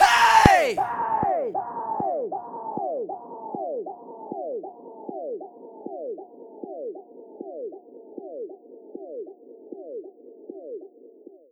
Track 11 - Vocal Hey 03.wav